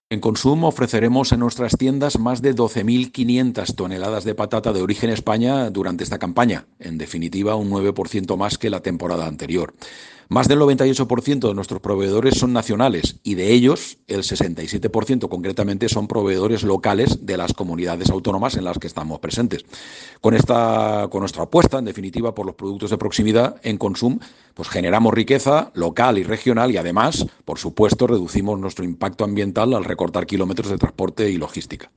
Corte de voz